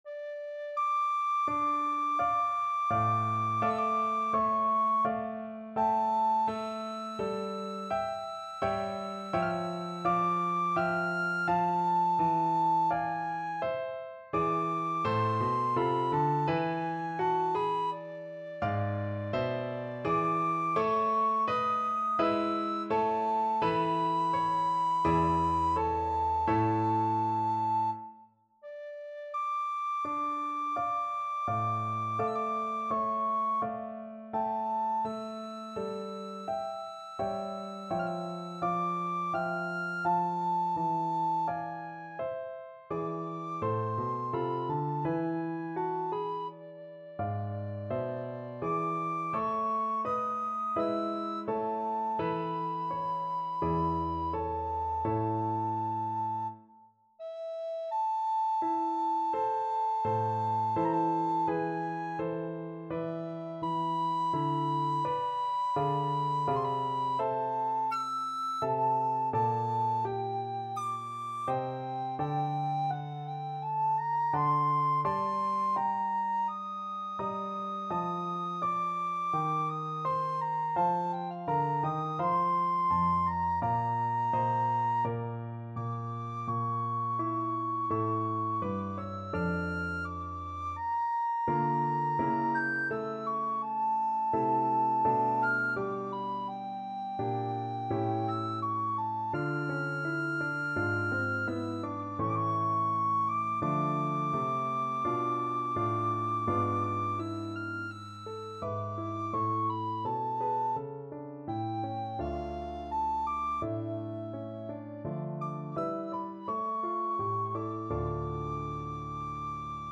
Classical Vivaldi, Antonio Sonata No. 5 in E Minor, Op. 14, First Movement Soprano (Descant) Recorder version
4/4 (View more 4/4 Music)
Largo =42
Classical (View more Classical Recorder Music)
vivaldi_sonata_em_op14_5_1_REC.mp3